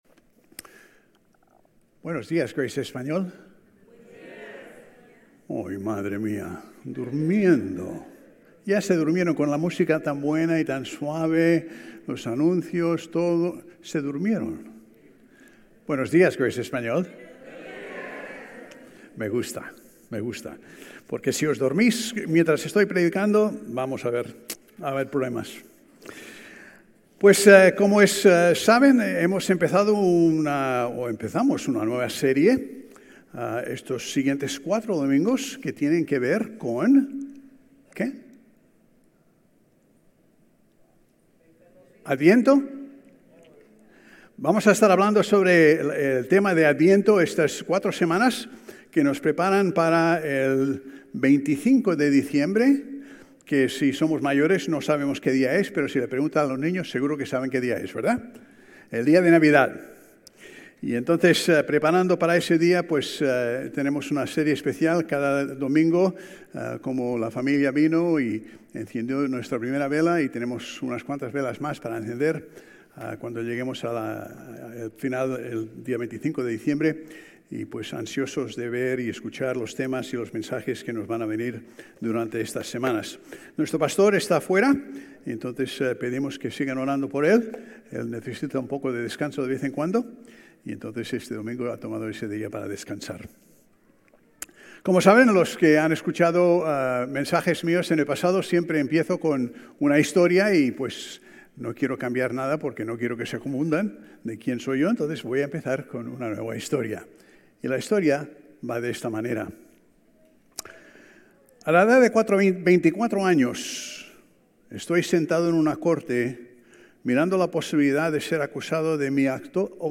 Sermones Grace Español 11_30 Grace Espanol Campus Dec 01 2025 | 00:35:41 1x 00:00 / 00:35:41 Subscribe Share RSS Feed Share Link Embed